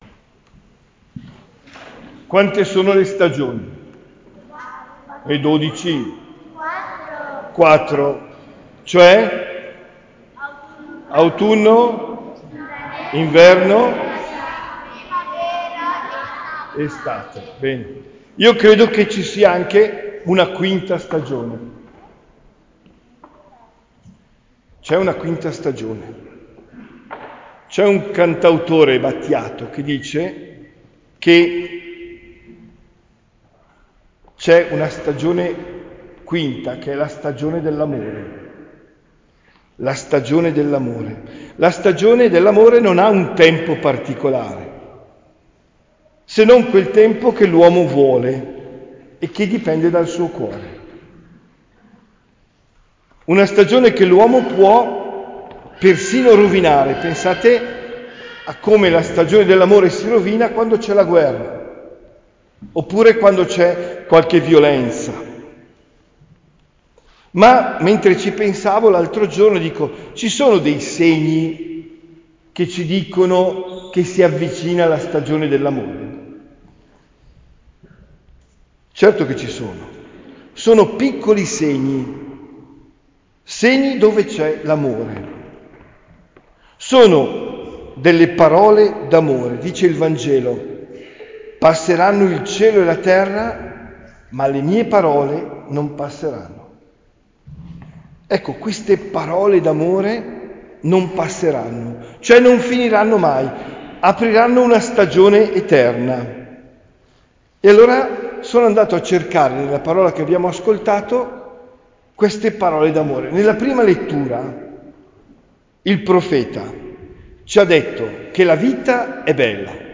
OMELIA DEL 17 NOVEMBRE 2024